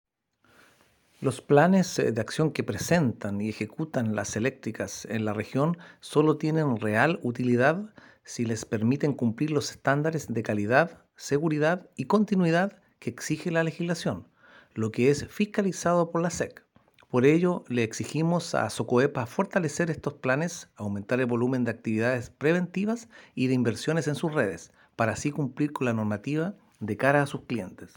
Cuña_DR_SEC_LosRios
Cuna_DR_SEC_LosRios.aac